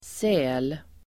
Ladda ner uttalet
Uttal: [sä:l]